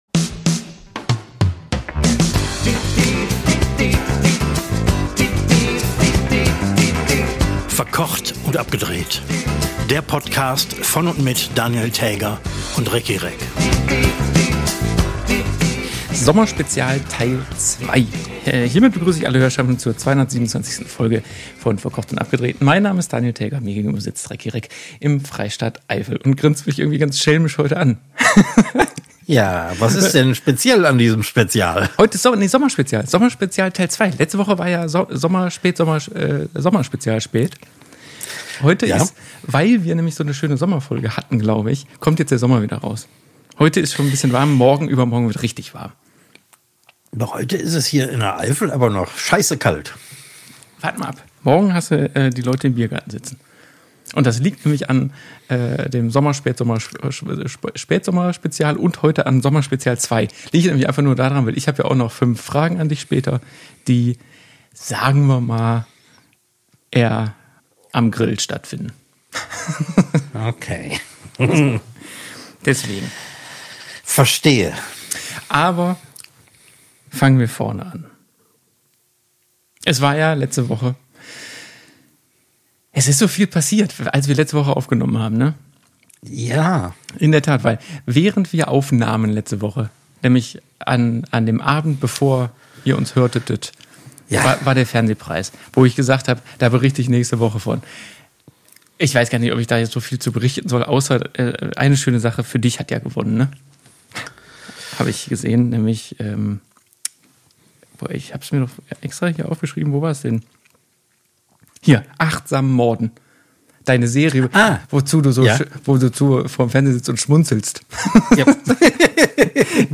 Nicht zu Ende sind aber die Möglichkeiten der technischen Probleme, die während jeder Aufnahme bis heute passieren können. So könnte es eventuell passiert sein, dass die 227. Folge „Verkocht und Abgedreht“ per Fernsprecher und zeitweise sogar ganz ohne Sichtverbindung zwischen Koch und Fernsehmann mit zwei Dosen und einer Kordel von Köln bis in die Eifel aufgenommen wurde.